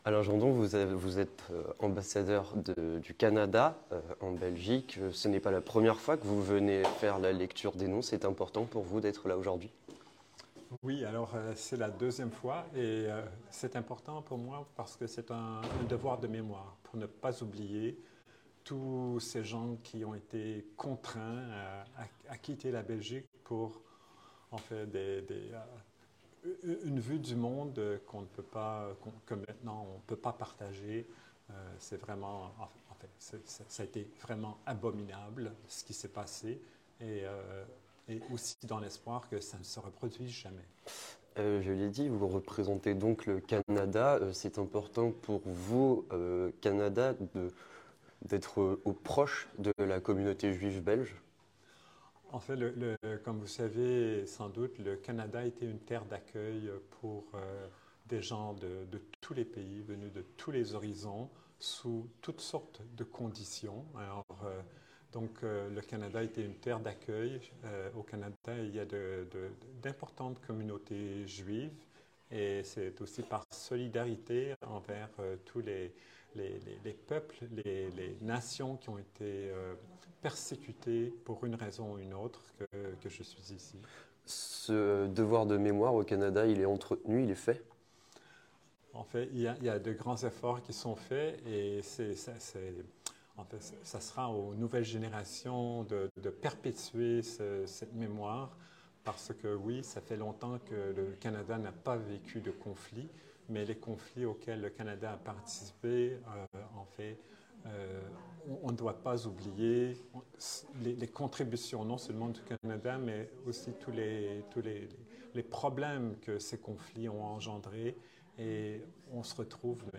Reportage à la lecture des noms (19/04/2023)
Reportage à la lecture des noms